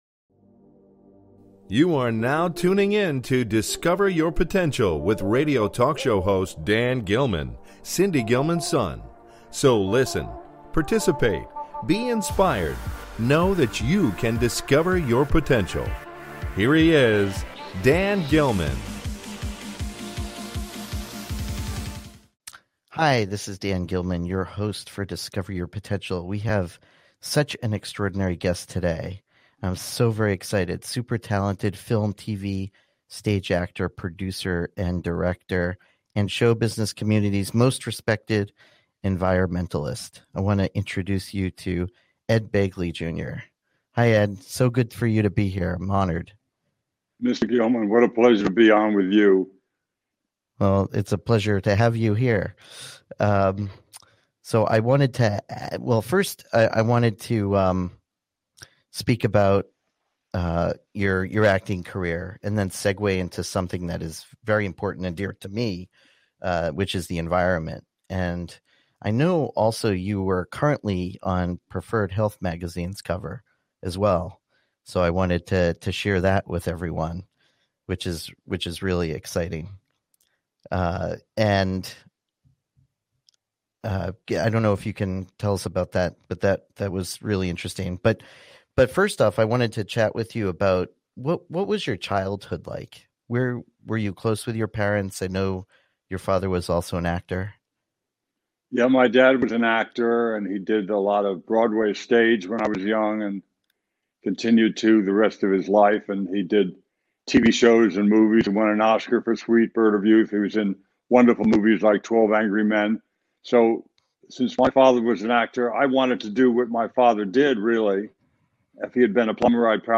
Guest, Ed Begley Jr.